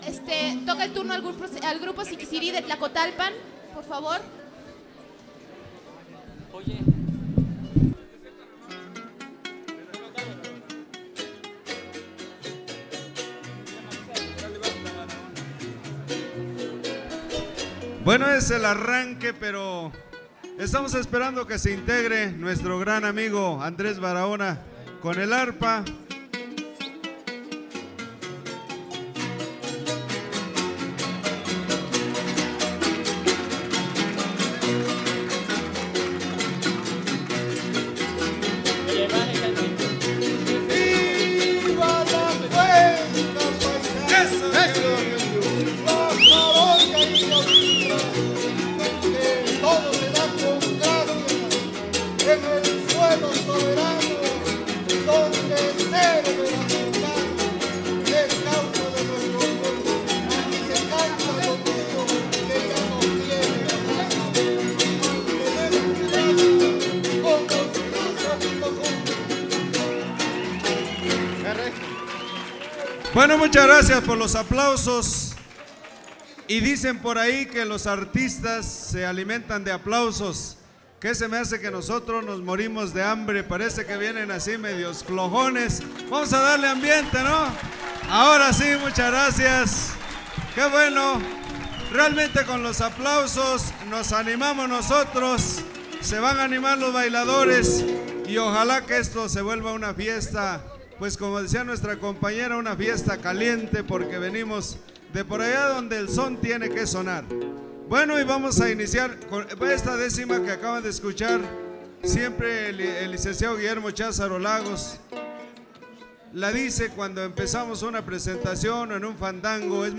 • Siquisirí (Grupo musical)
Sexto Encuentro de Etnomusicología. Fandango de clausura